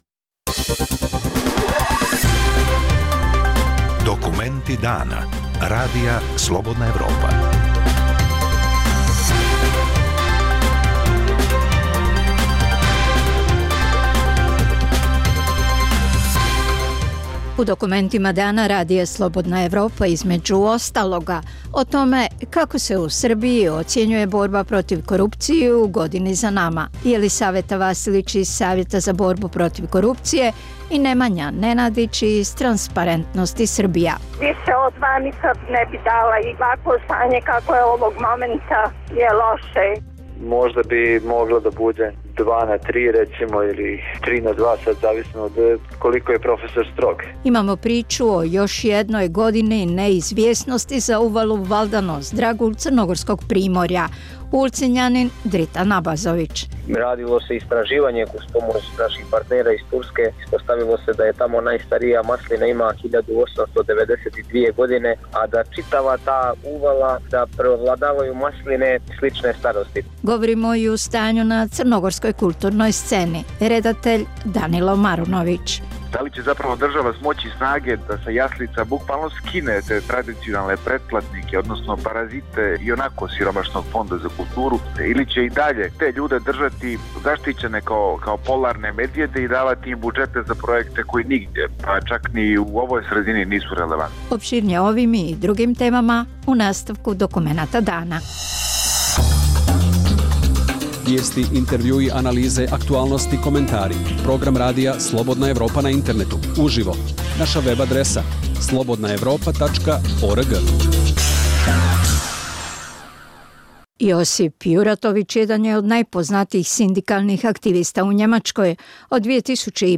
- Razgovor s Josipom Juratovićem, zastupnikom Socijaldemokrata u njemačkom parlamentu.